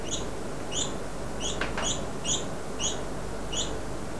Here are a few samples of birds songs I recorded in the hotel's gardens.
Baby bulbul  (96 Ko)
baby_bulbul.wav